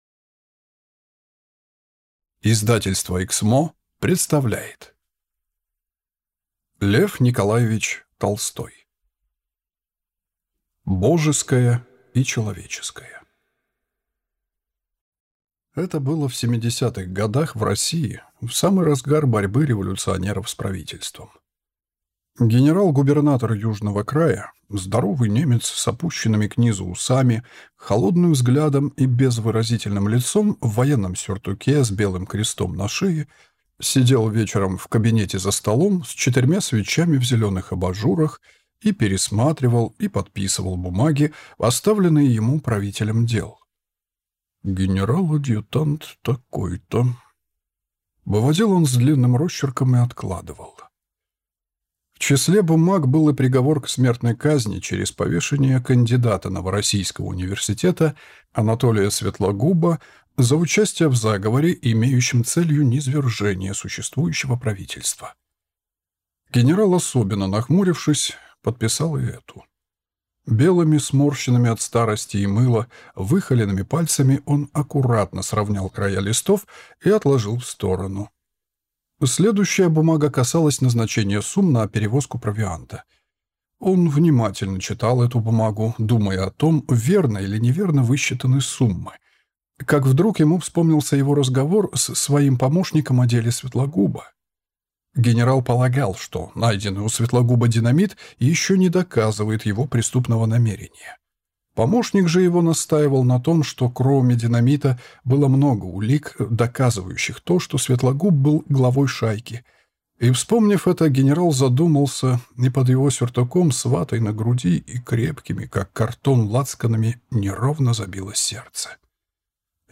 Аудиокнига Божеское и человеческое | Библиотека аудиокниг